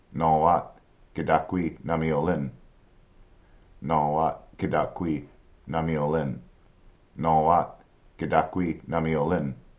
nô-wat  kda-kwi   na-mi ho-len